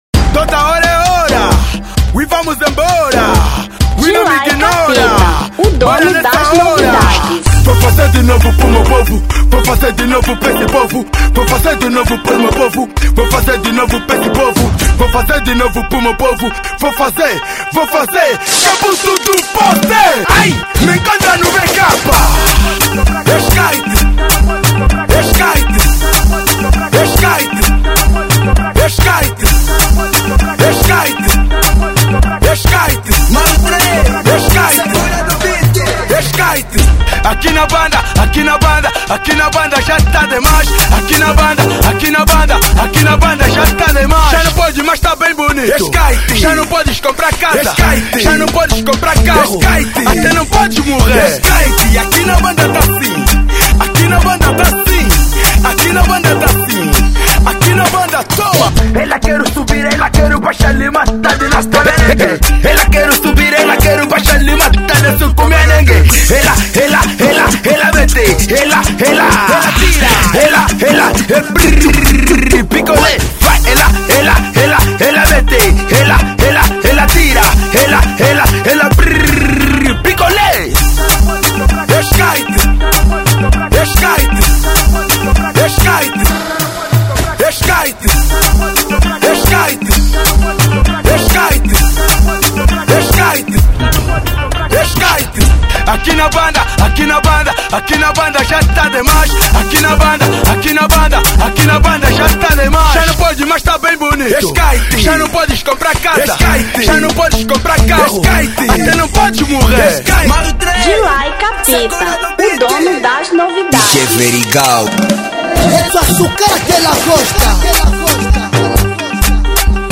Afro Edit 2025